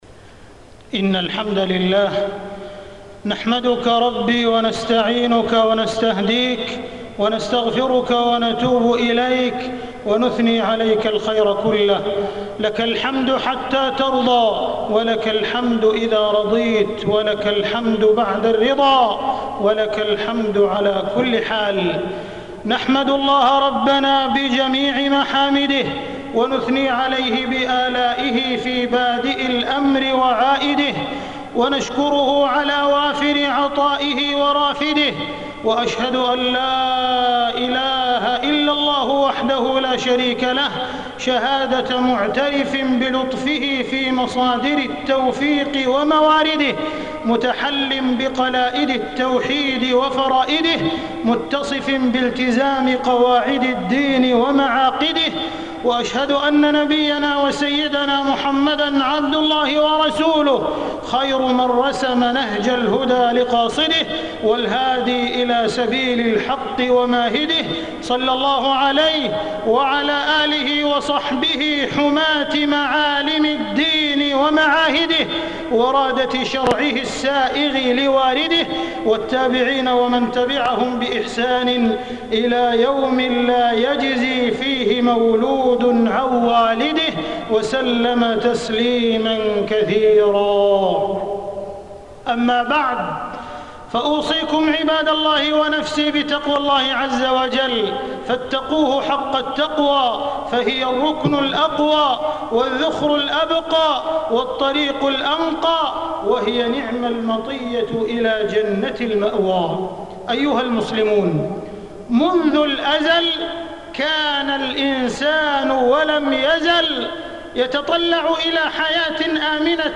تاريخ النشر ١٩ ذو القعدة ١٤٢٥ هـ المكان: المسجد الحرام الشيخ: معالي الشيخ أ.د. عبدالرحمن بن عبدالعزيز السديس معالي الشيخ أ.د. عبدالرحمن بن عبدالعزيز السديس قضية مسلمة